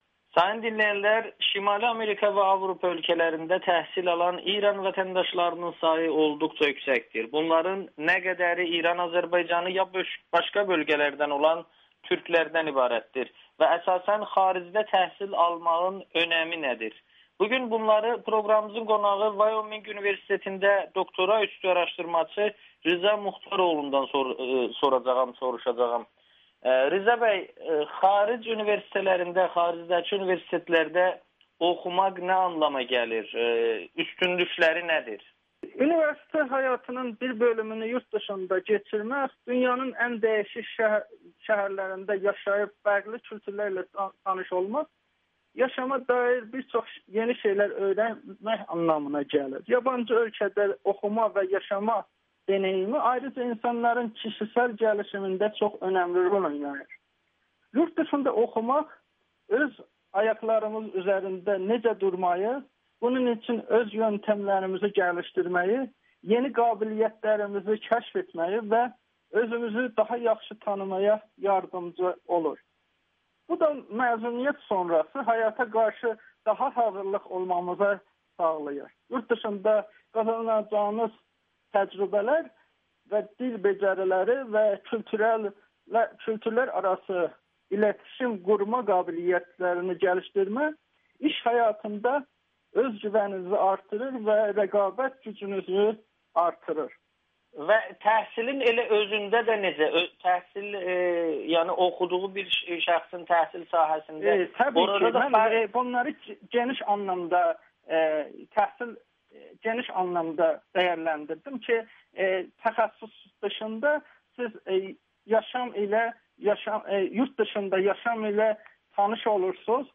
Xaricdə təhsil alan güneyli tələbələrin sayı azdır [Audio-Müsahibə]